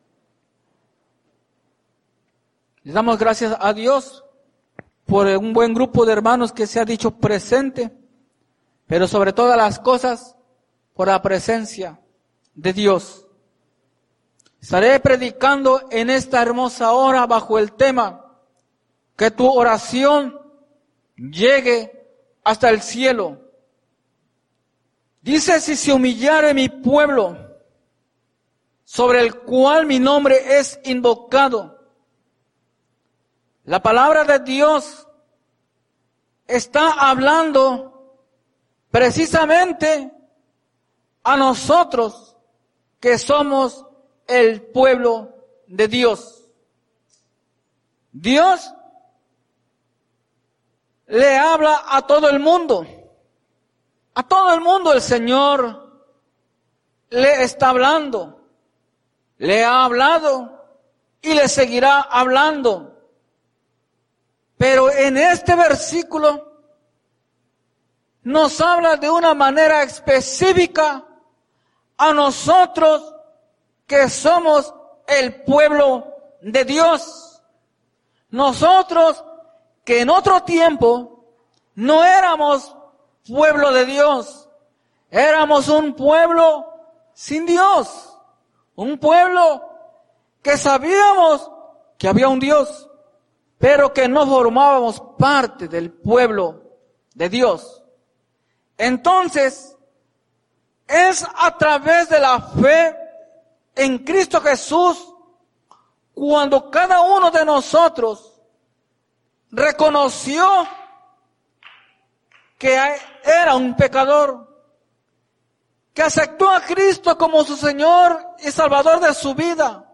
Que tu oración llegue hasta el cielo Predica